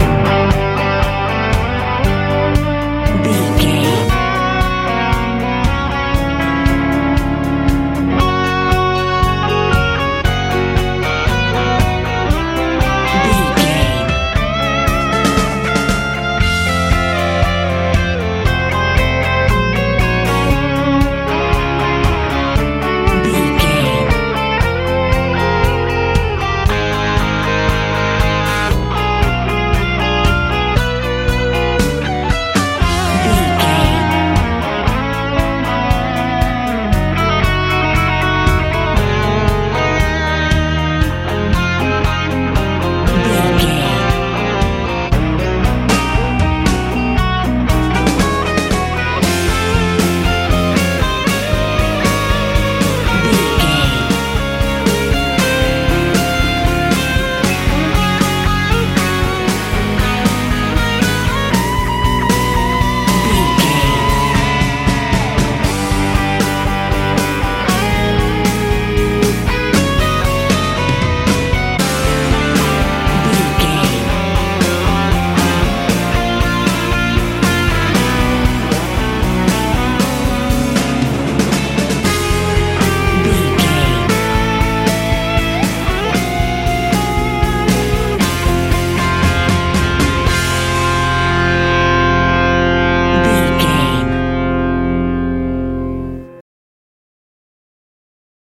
med rock feel
Ionian/Major
driving
groovy
electric guitar
bass guitar
drums
powerful
positive
mellow